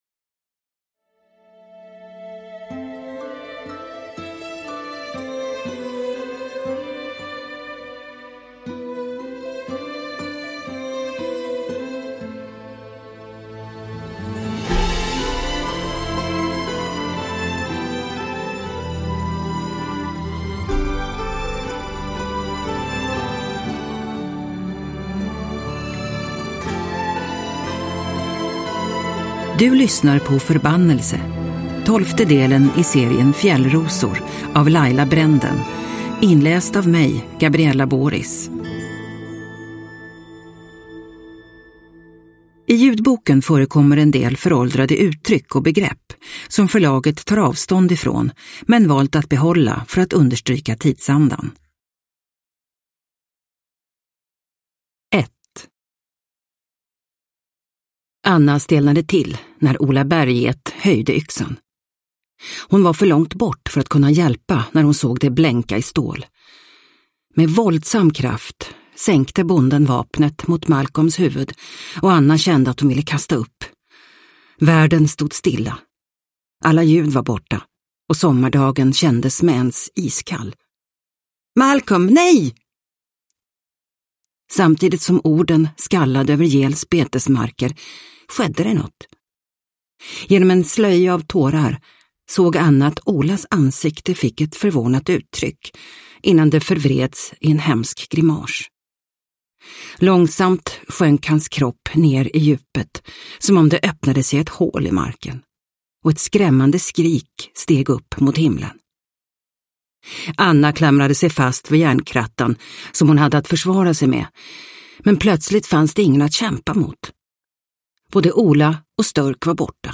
Förbannelse – Ljudbok